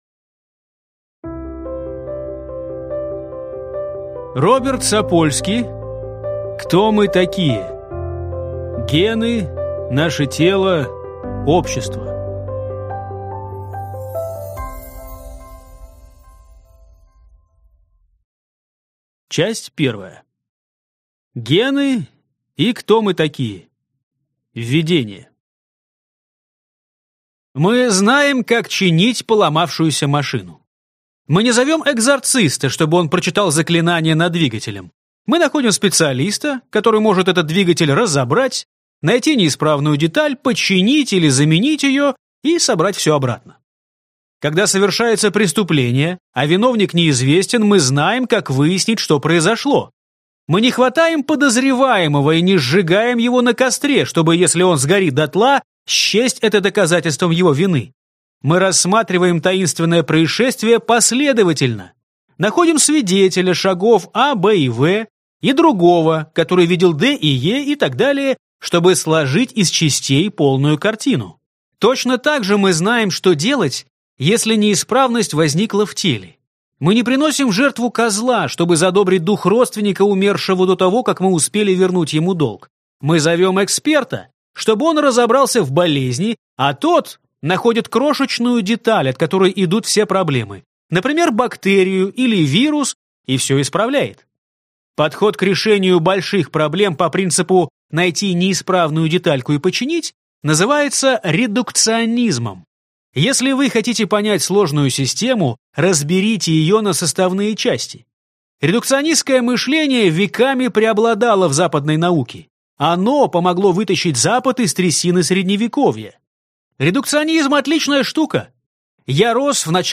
Аудиокнига Кто мы такие? Гены, наше тело, общество | Библиотека аудиокниг